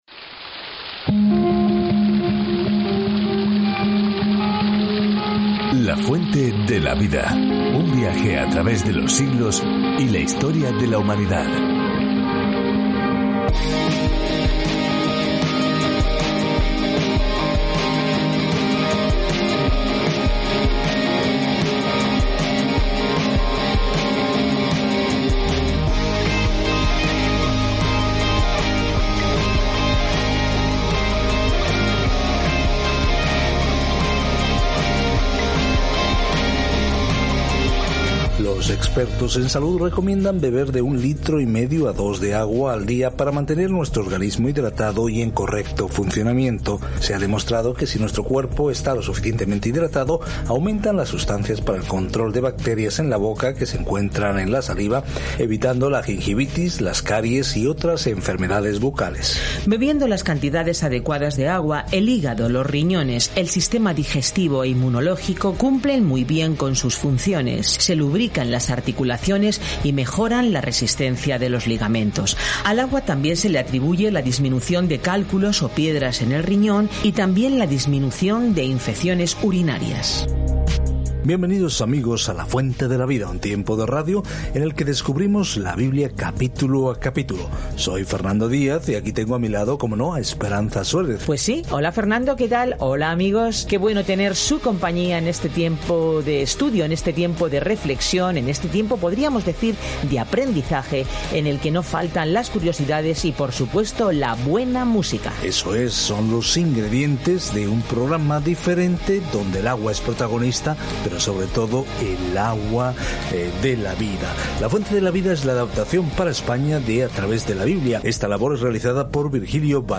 Scripture John 12:44-50 John 13:1-10 Day 26 Start this Plan Day 28 About this Plan Las buenas noticias que Juan explica son únicas de los otros evangelios y se centran en por qué debemos creer en Jesucristo y cómo tener vida en este nombre. Viaja diariamente a través de Juan mientras escuchas el estudio de audio y lees versículos seleccionados de la palabra de Dios.